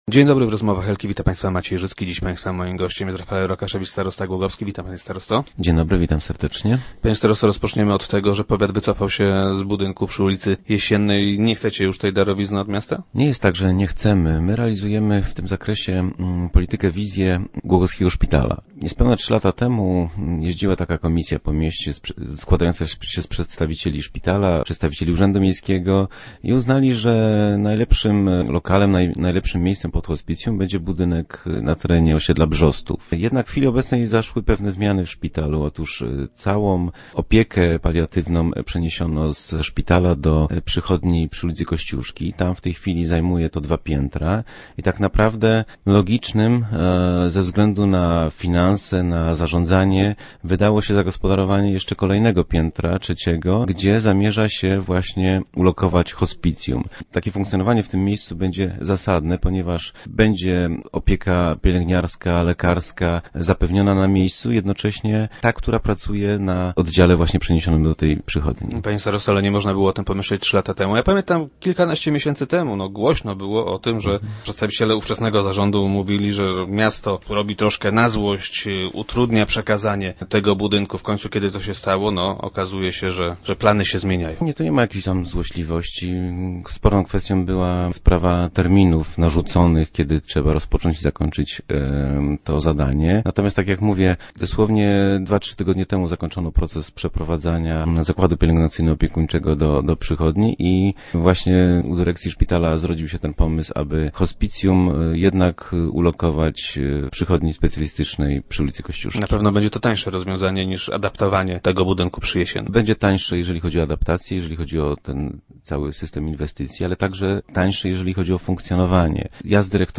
- To rozwiązanie jest zdecydowanie tańsze - twierdzi starosta Rafael Rokaszewicz, który był gościem dzisiejszych Rozmów Elki.